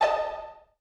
ihob/Assets/Extensions/CartoonGamesSoundEffects/Suspicious_v1/Suspicious_v3_wav.wav at master
Suspicious_v3_wav.wav